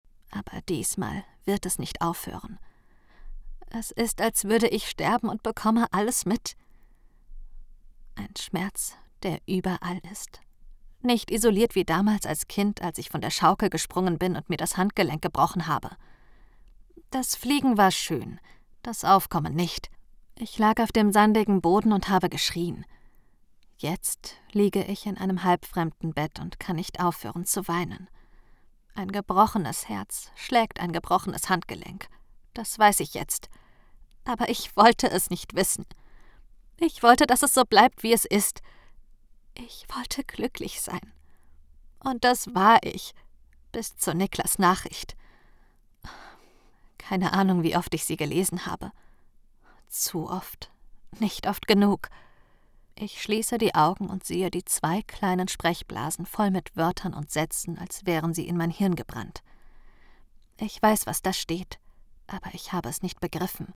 dunkel, sonor, souverän, markant, sehr variabel
Hörbuch Young Adult Liebesroman
Audiobook (Hörbuch)